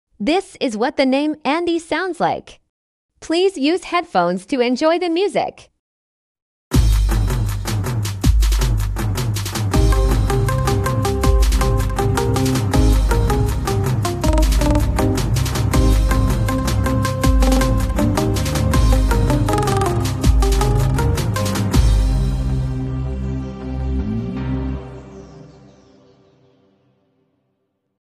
How the name Andy sounds like as midi art.